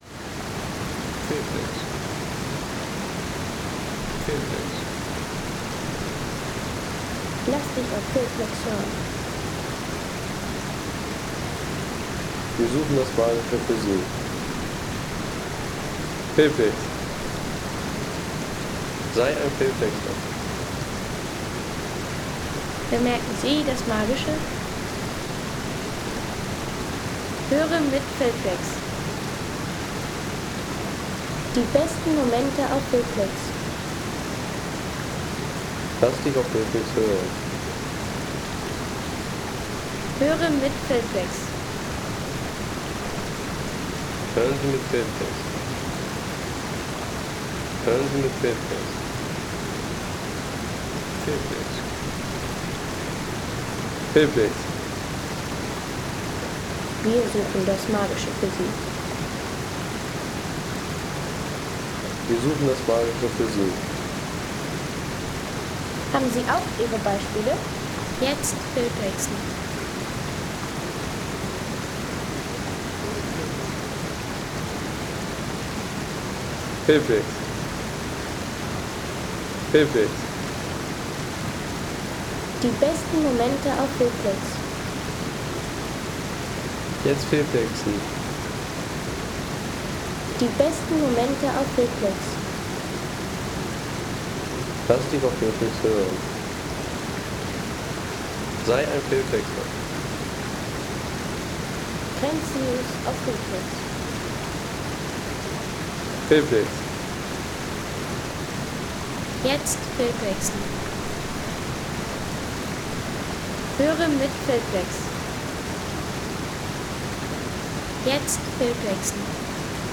Schlatenbach-Wasserfall, 2187m